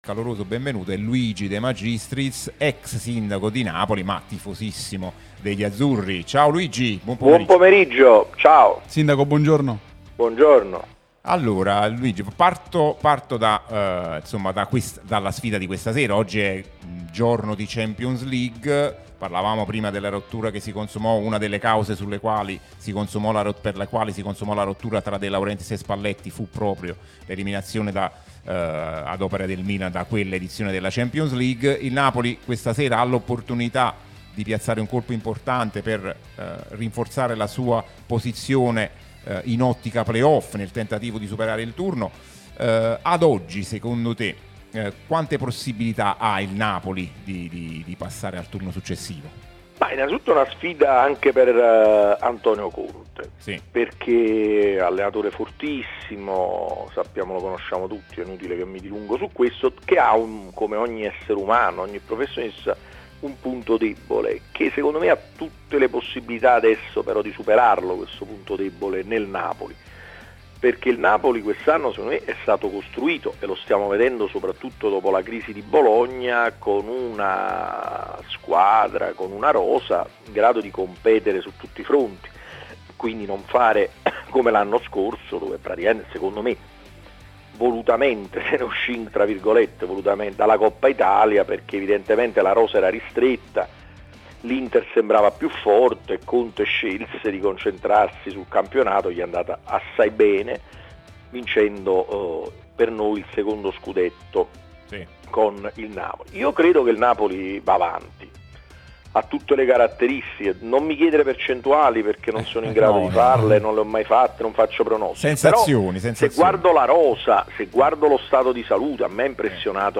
Lo ha detto l'ex sindaco di Napoli, Luigi De Magistris , intervenuto nel corso di Cronache Azzurre, trasmissione sulla nostra Radio Tutto Napoli , prima radio tematica sul Napoli, in onda tutto il giorno, che puoi ascoltare/vedere qui sul sito o sulle app ( qui per Iphone/Ipad o qui per Android ).